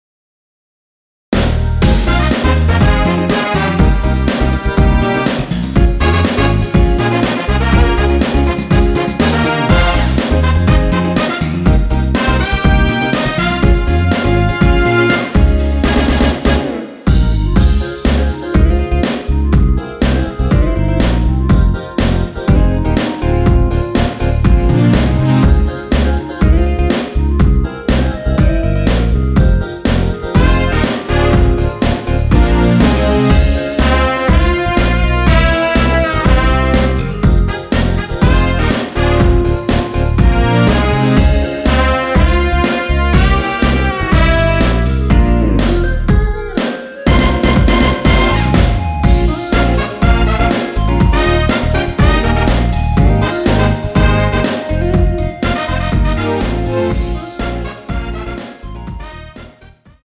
원키에서(-2)내린 멜로디 포함된 MR입니다.
Fm
앞부분30초, 뒷부분30초씩 편집해서 올려 드리고 있습니다.
중간에 음이 끈어지고 다시 나오는 이유는